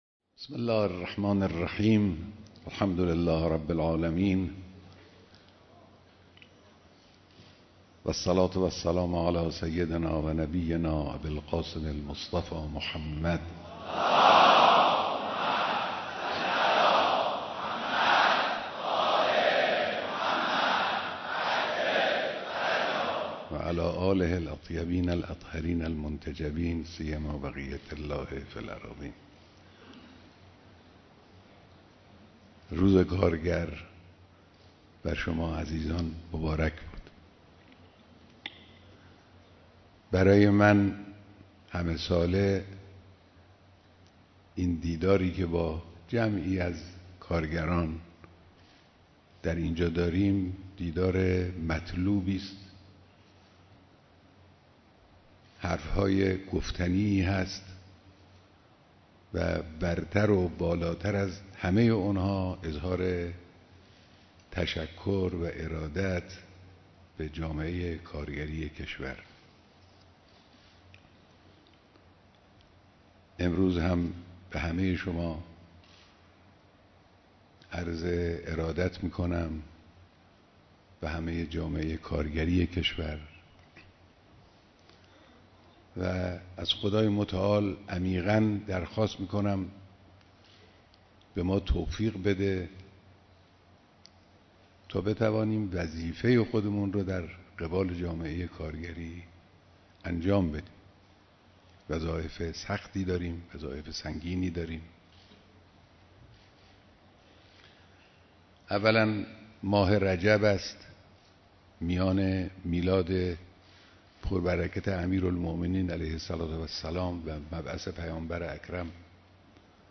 بیانات در دیدار هزاران نفر از کارگران سراسر کشور